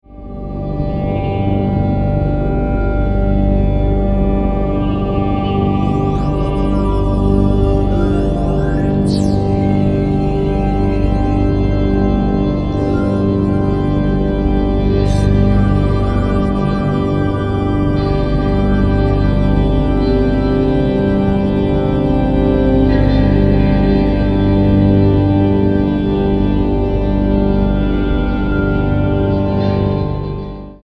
Dark rawk for doobious times.
guitars, keys
keys, voices